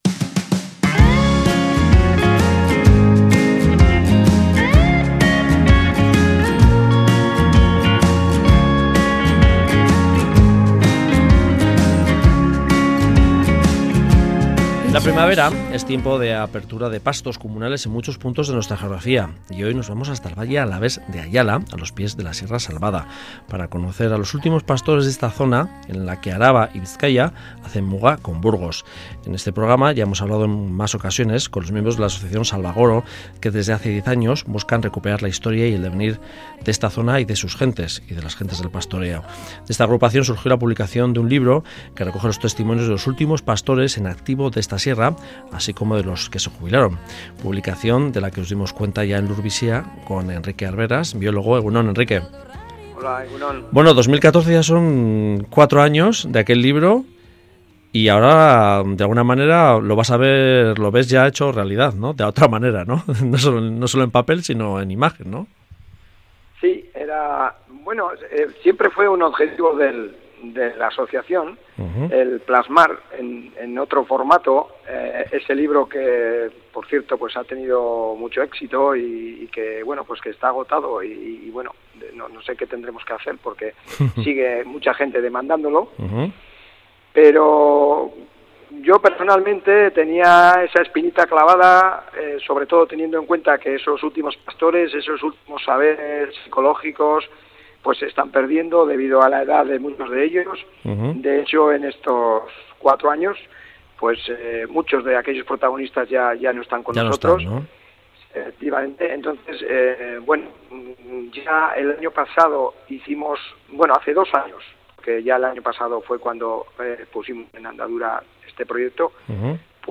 Documental sobre los últimos pastores de Sierra Salvada